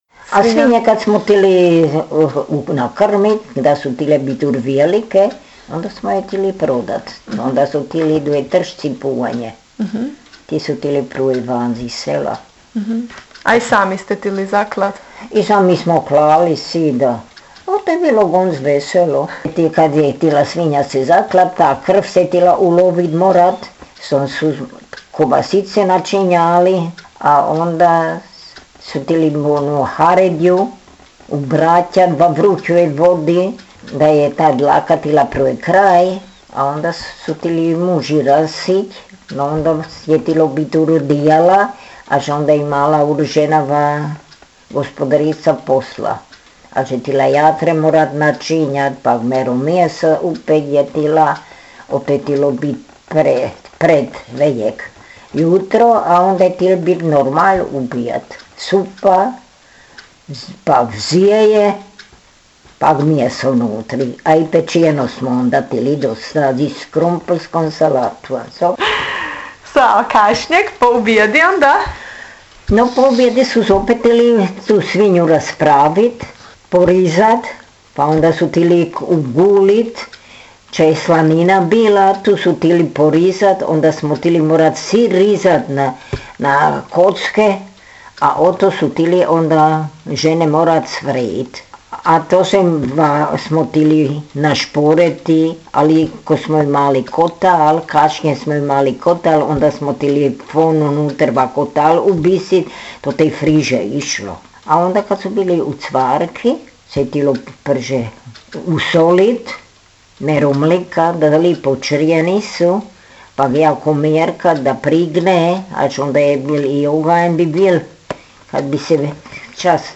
jezik naš, jezik naš gh dijalekti
Gerištof – Govor